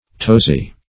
Tozy \To"zy\